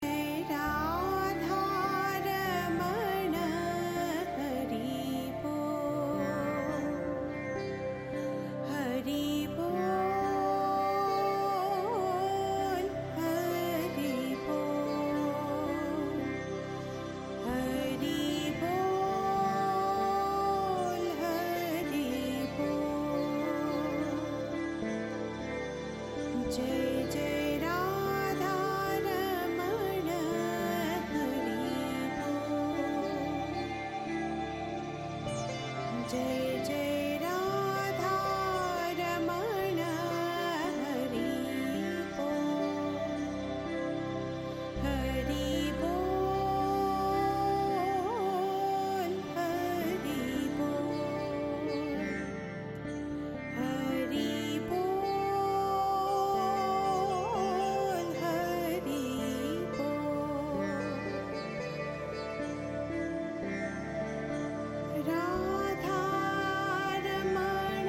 Deep Meditative journey
chants and mantras